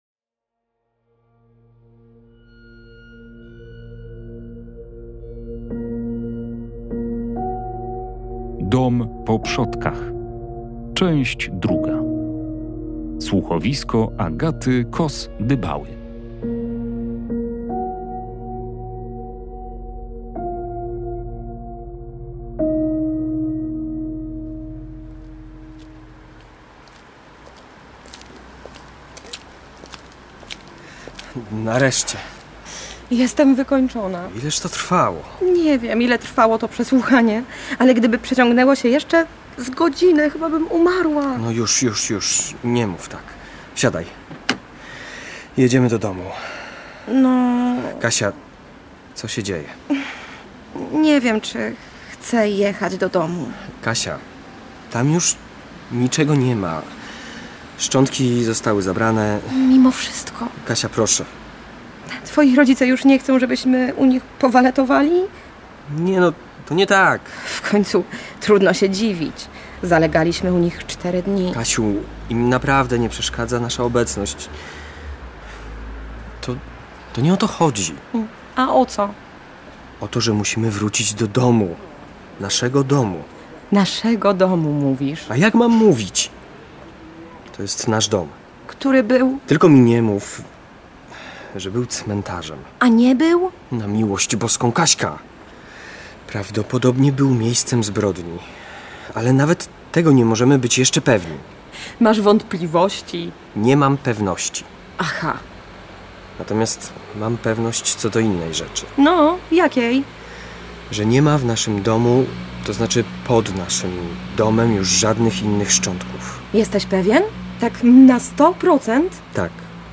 Prezent od dziadka, czyli piękny, stary dom to dla bohaterów słuchowiska kryminalnego "Dom po przodkach" nie tylko radość.